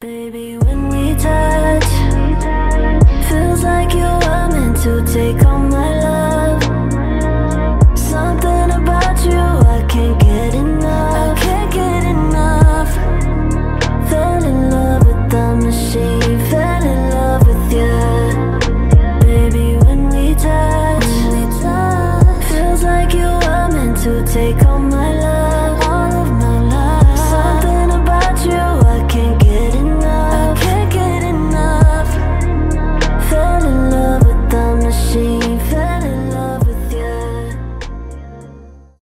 красивый женский голос , чувственные , trap , медленные
alternative , rnb